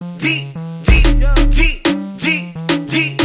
Play, download and share Τζιτζίκι original sound button!!!!